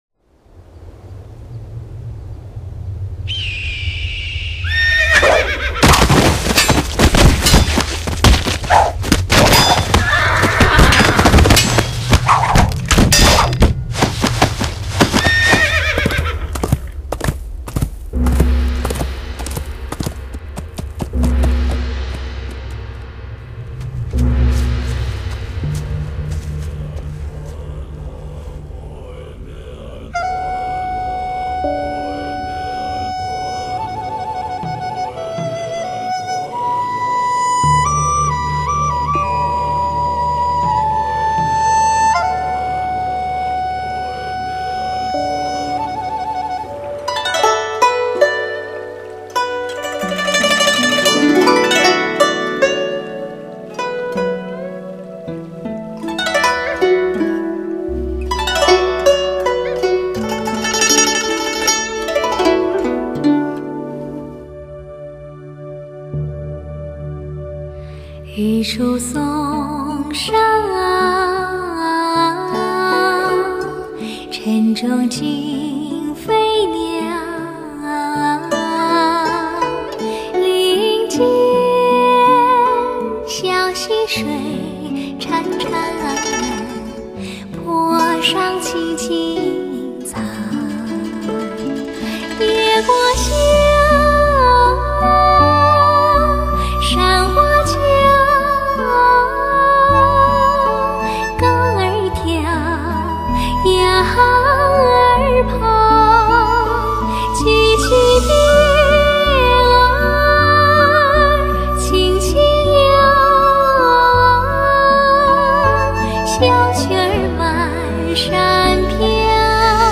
夜幕降临，寂静的空间回荡你那委婉的歌喉，不觉中我发现好歌是要用心去感应的。
音效不错,让我听到了什么是高品质